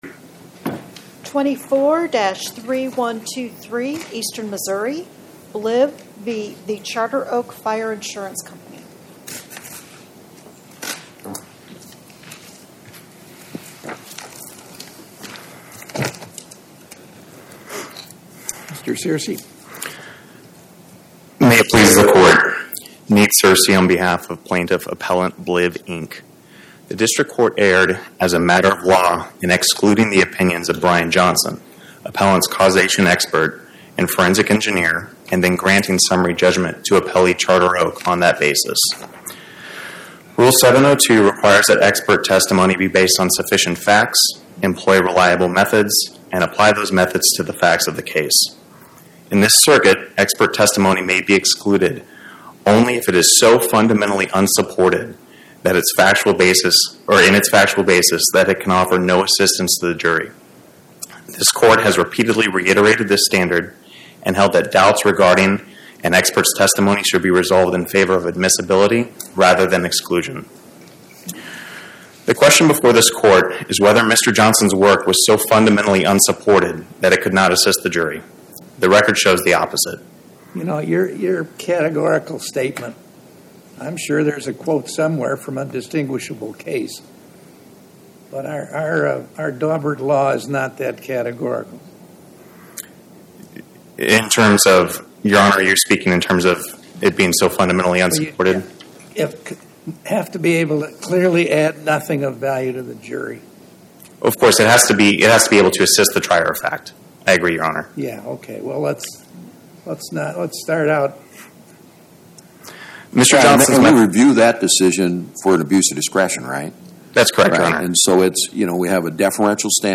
My Sentiment & Notes 24-3123: Bliv, Inc. vs The Charter Oak Fire Insurance Company Podcast: Oral Arguments from the Eighth Circuit U.S. Court of Appeals Published On: Wed Sep 17 2025 Description: Oral argument argued before the Eighth Circuit U.S. Court of Appeals on or about 09/17/2025